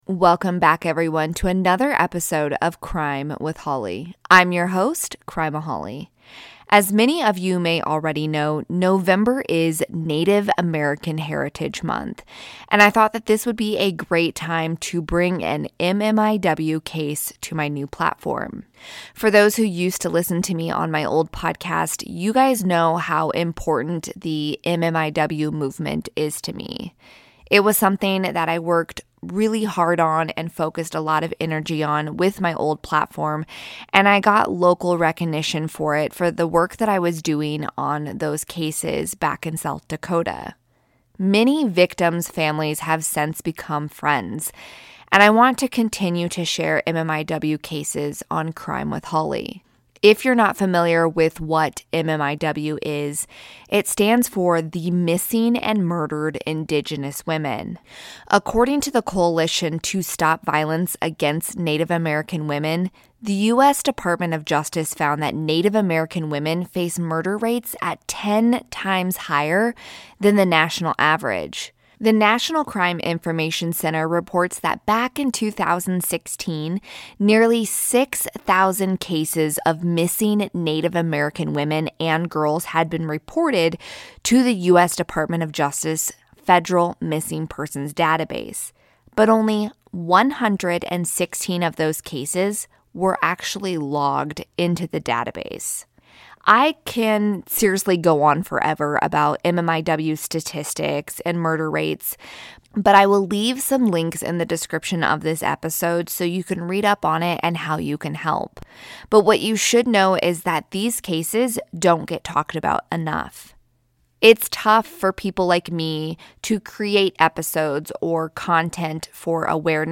New introduction and ending have since been recorded and added, audio levels may reflect the different recordings.